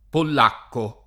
polacco [ pol # kko ]